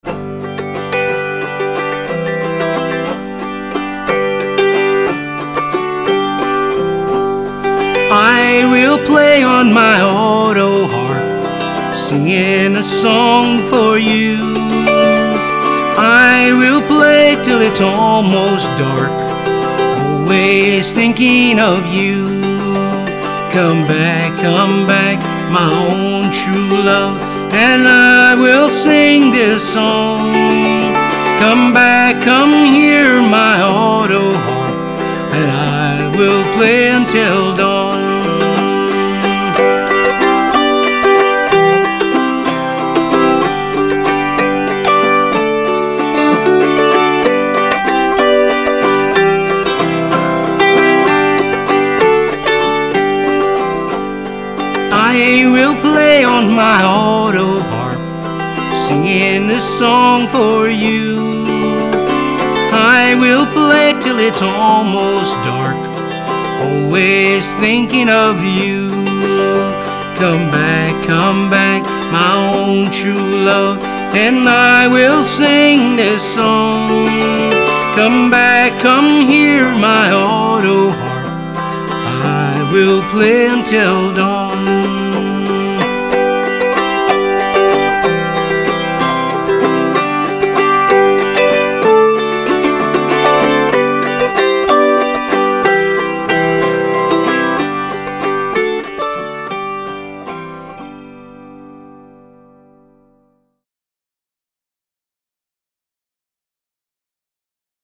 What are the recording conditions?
Recorded at Clinton Studios New York City